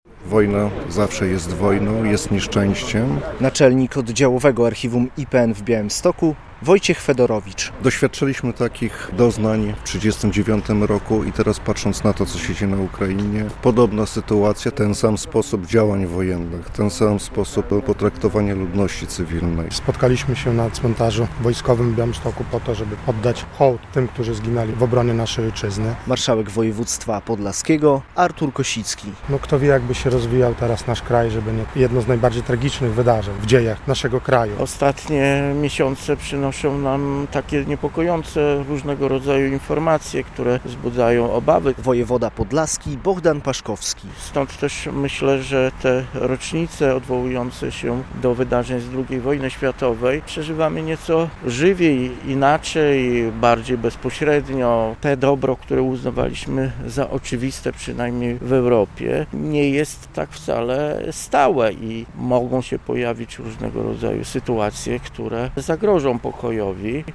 Uroczystości z okazji 83. rocznicy wybuchu II wojny światowej - relacja